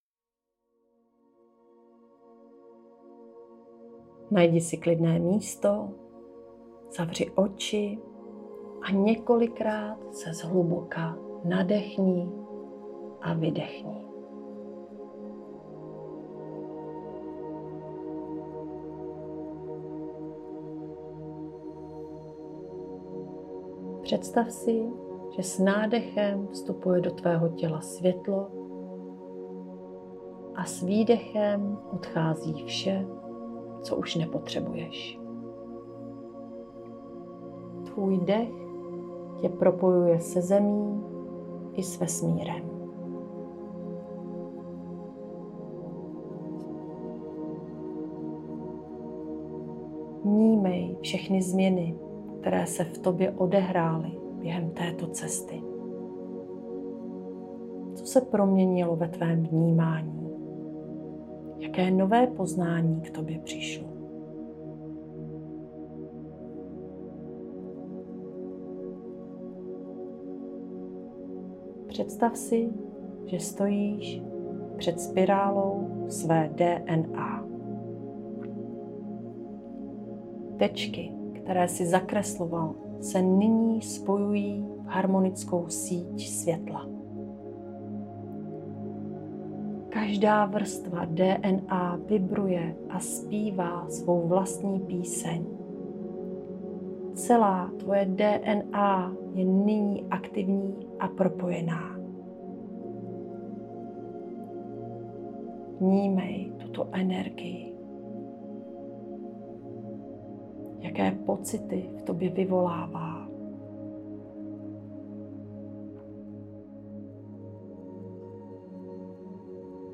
Meditace - závěr